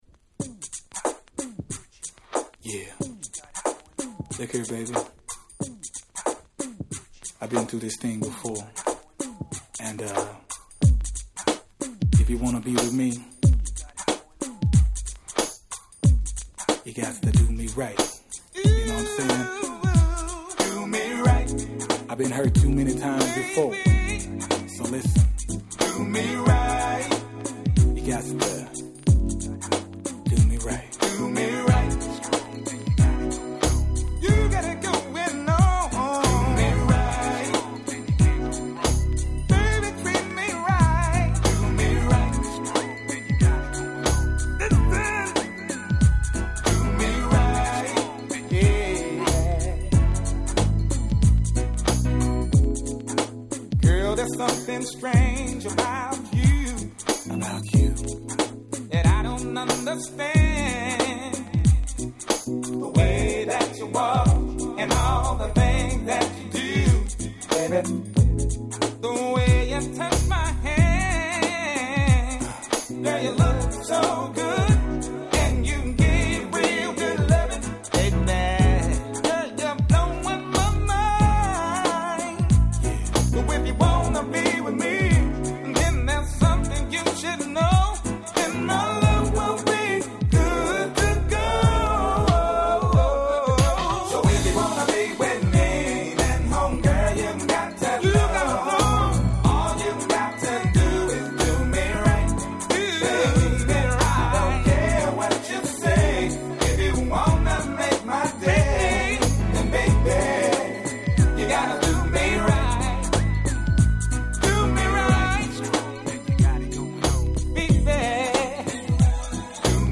97' Sweden Press Only Nice R&B !!
USの男性Vocal物の90's R&Bがお好きな方は絶対にご試聴下さい！！
SmoothでGroovy、基本に忠実な「教科書通り」の正統派R&B。